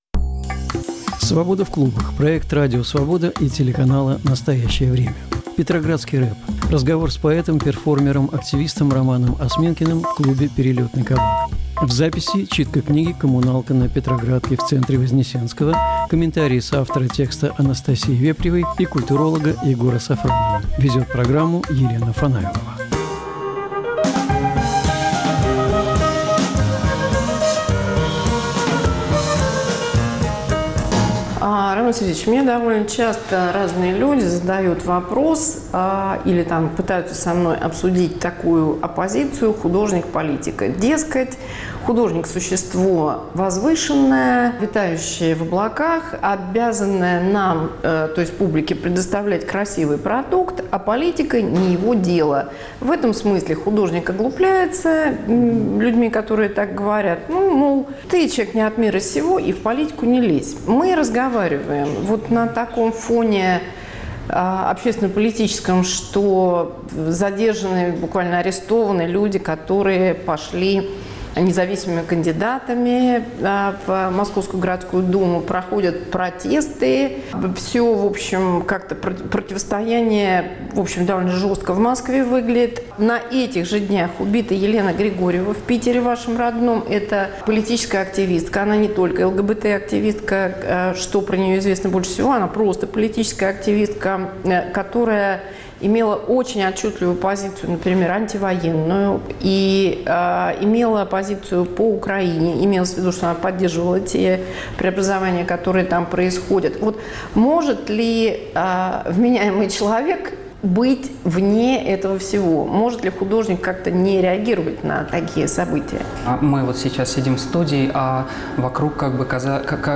“Петроградский рэп”. Разговор с поэтом